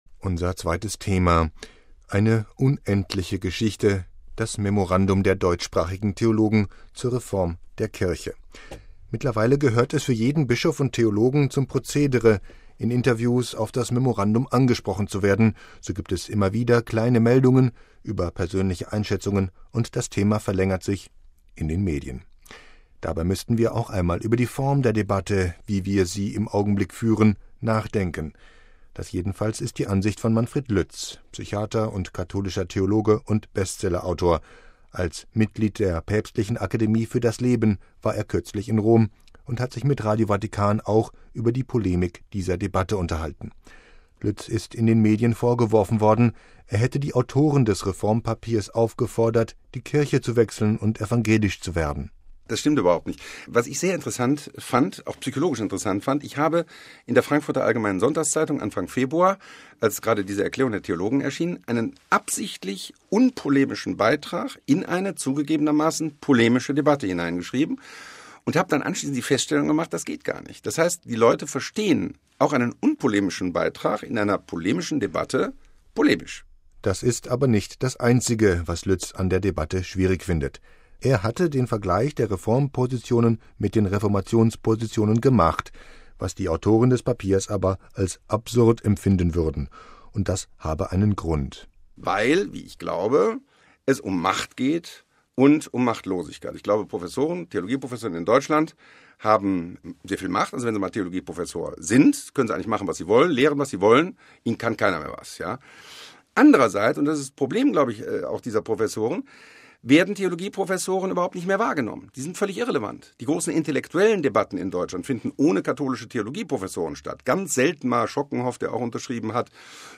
MP3 Eine unendliche Geschichte – das Memorandum der deutschsprachigen Theologen zur Reform der Kirche.